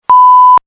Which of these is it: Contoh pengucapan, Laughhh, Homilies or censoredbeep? censoredbeep